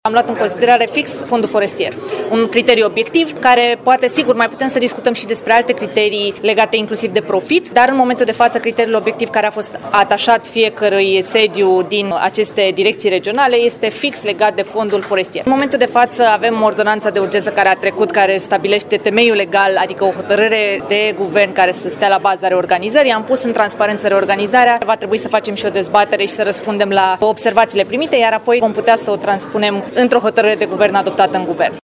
Prezentă la Timișoara, ministrul Diana Buzoianu a explicat că alegerea are la bază criteriul obiectiv al al suprafeței împădurite, județul Caraș-Severin fiind al doilea la nivel național ca fond forestier.